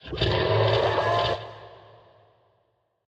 Minecraft Version Minecraft Version 1.21.5 Latest Release | Latest Snapshot 1.21.5 / assets / minecraft / sounds / mob / horse / skeleton / water / idle3.ogg Compare With Compare With Latest Release | Latest Snapshot